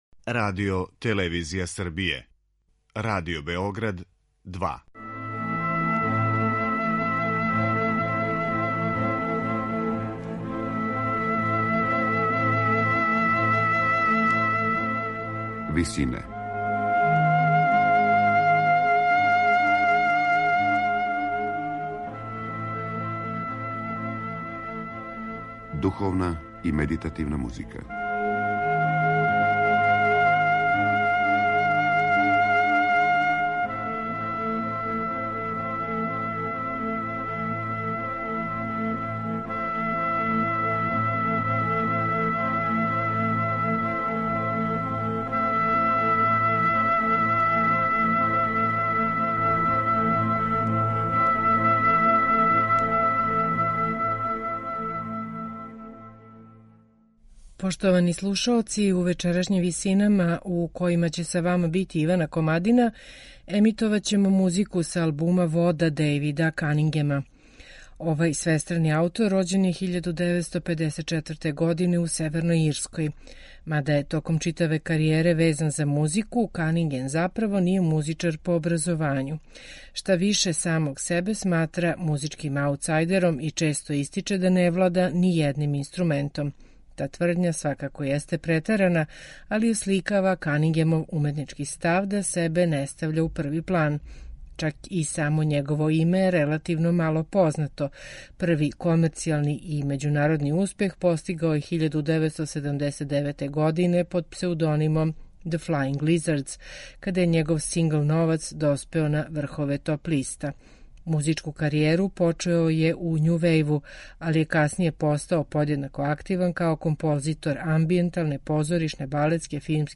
медитативне и духовне композиције